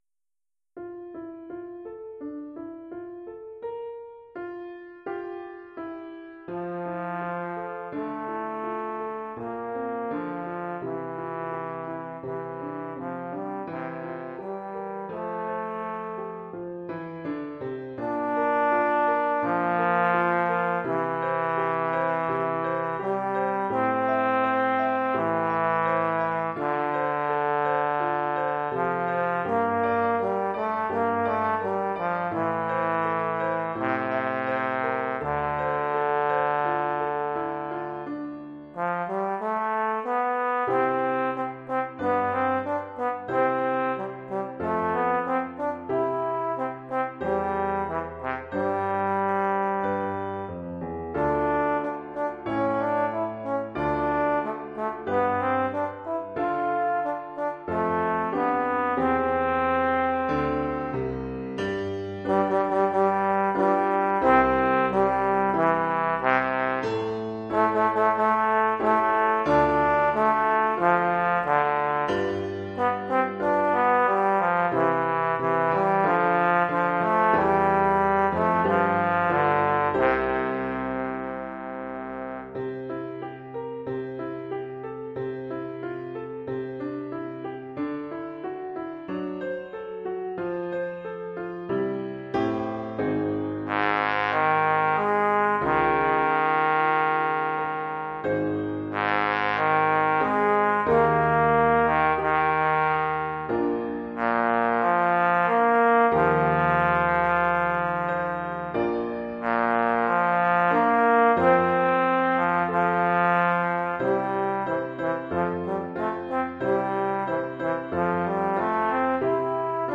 Oeuvre pour trombone et piano.
Niveau : élémentaire.